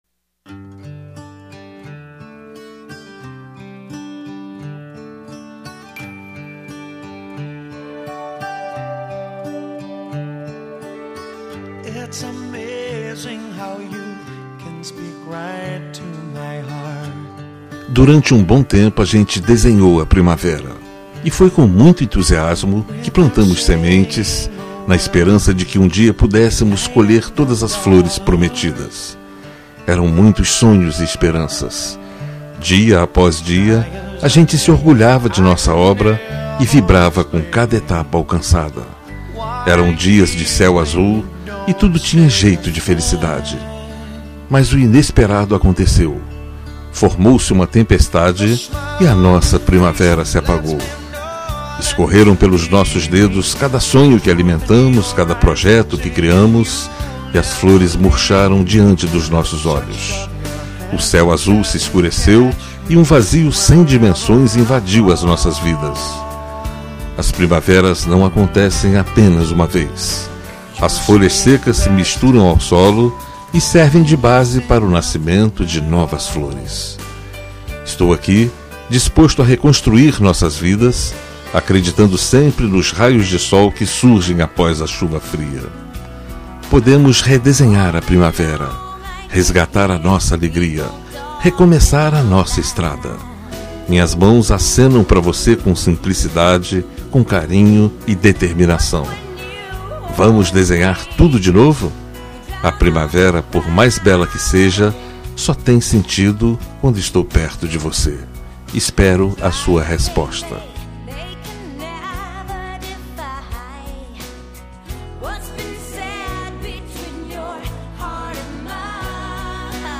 Telemensagem de Reconciliação – Voz Masculina – Cód: 7557